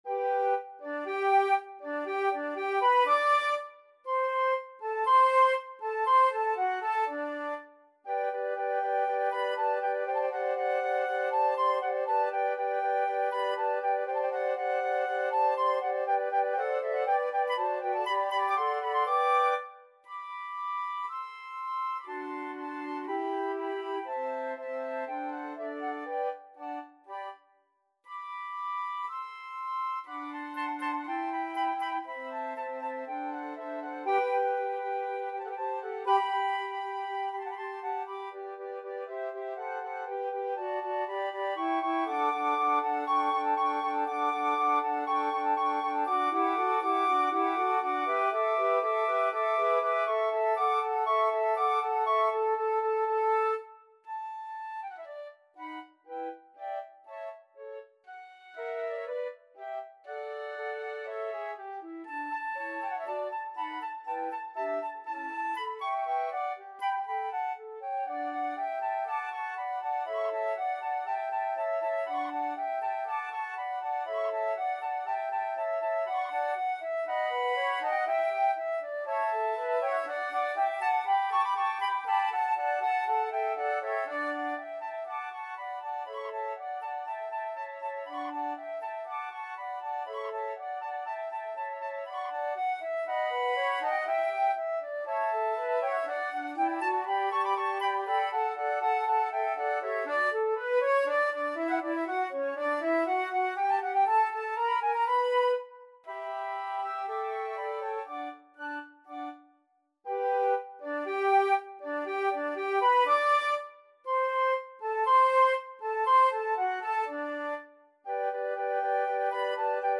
Free Sheet music for Flute Quartet
Flute 1Flute 2Flute 3Flute 4
G major (Sounding Pitch) (View more G major Music for Flute Quartet )
Allegro (View more music marked Allegro)
4/4 (View more 4/4 Music)
Classical (View more Classical Flute Quartet Music)